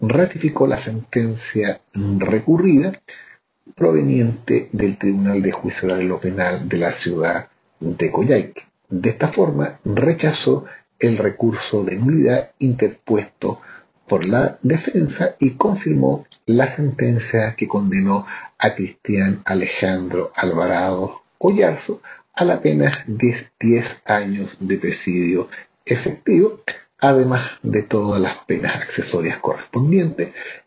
Respecto del caso el Ministro Vocero de la Corte de Apelaciones de Puerto Montt, Patricio Rondinni, confirmó la sentencia y dijo que se rechazó el recurso de nulidad.